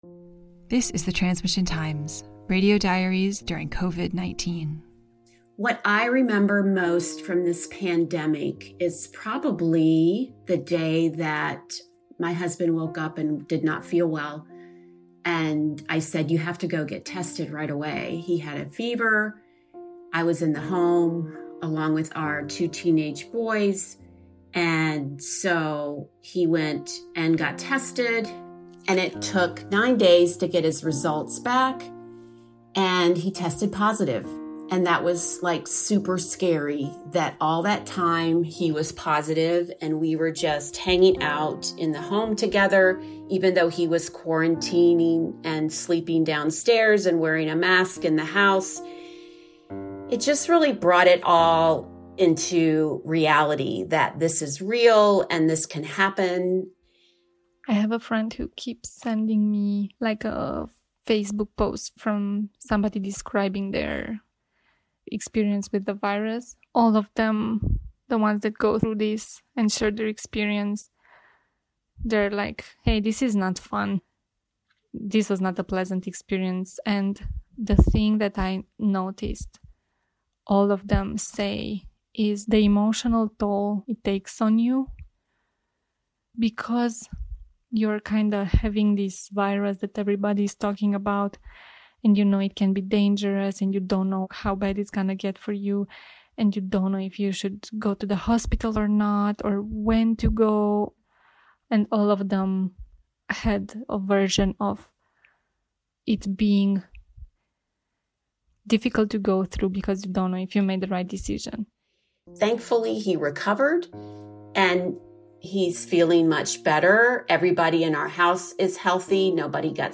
This episode is a snapshot of what this pandemic is like right now and has been like over the last six months told through the audio diaries of people around the world. Audio diaries from around the world give us a glimpse of daily life during the pandemic. In these non-narrated, sound-rich snapshots of life we hear the joys and celebrations, the challenges and desperations, of mothers and fathers, sons and daughters, old and young.